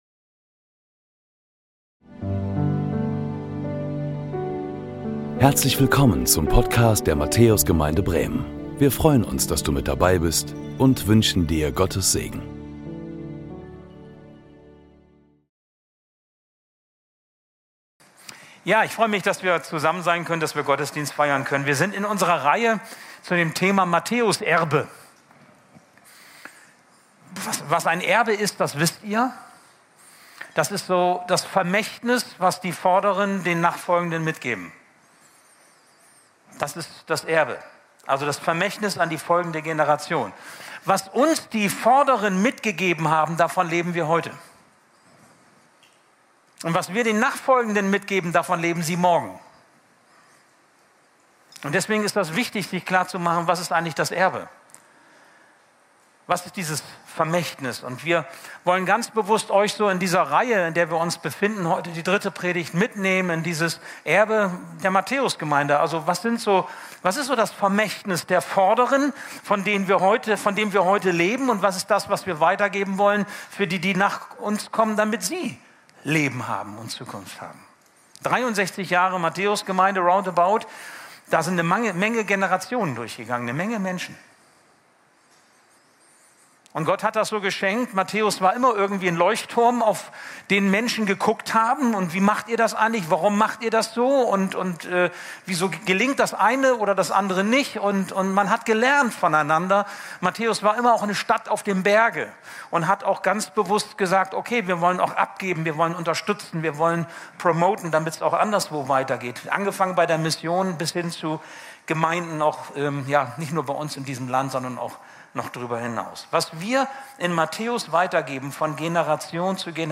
Predigten der Matthäus Gemeinde Bremen